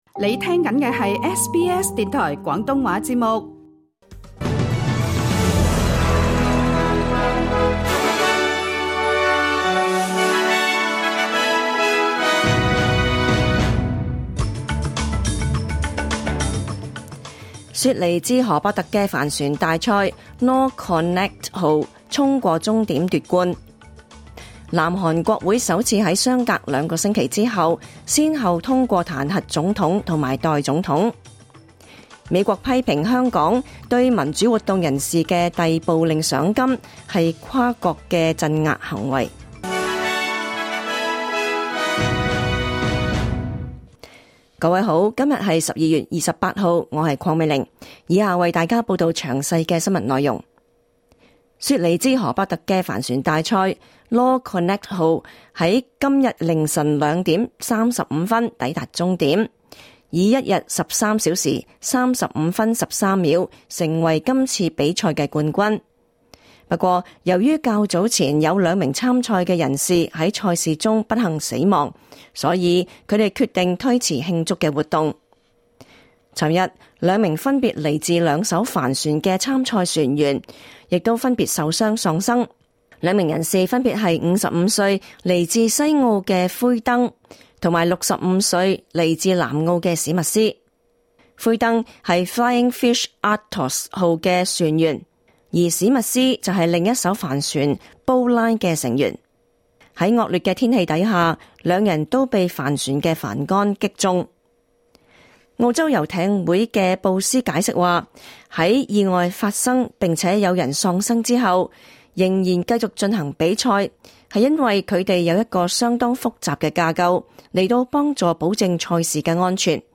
2024 年 12 月 28 日 SBS 廣東話節目詳盡早晨新聞報道。